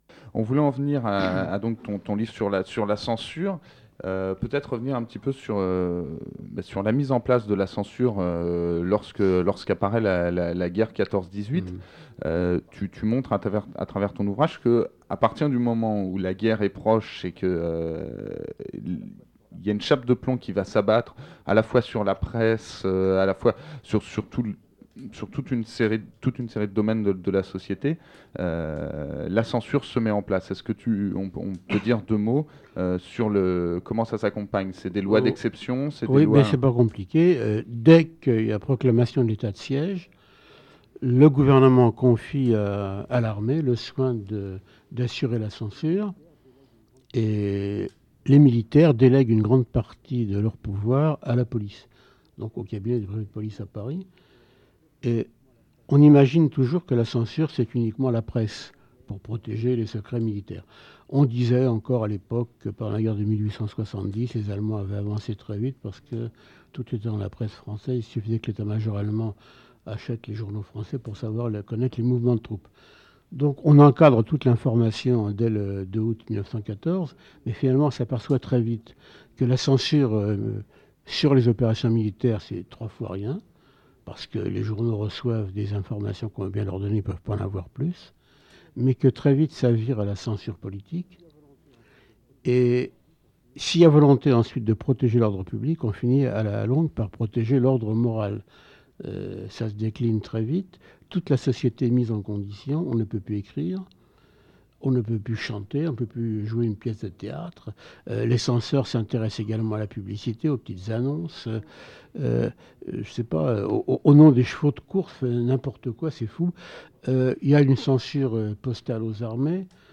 2e partie : entretien avec Rajsfus et Siné